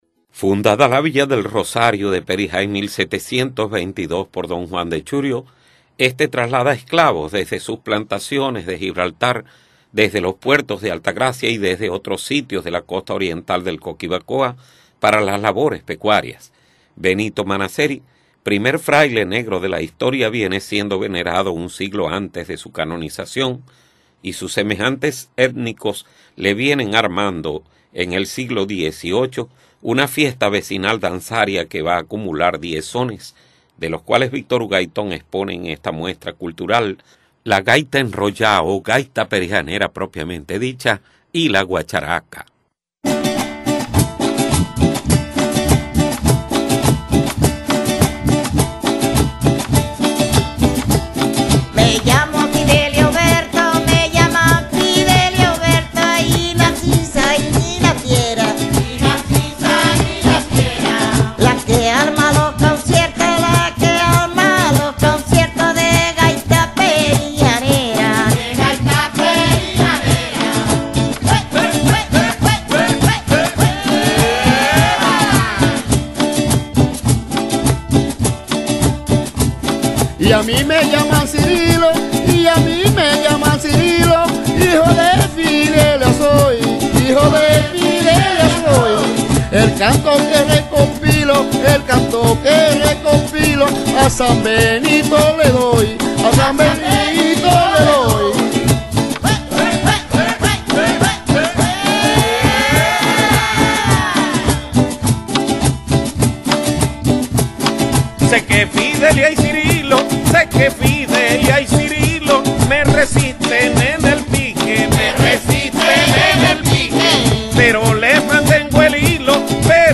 Con una estructura r�tmica binaria de 2X4
tambores de Chimbanguele, tambor de doble parche y doble baqueta y el Cuatro
catedragaitaperijanera.mp3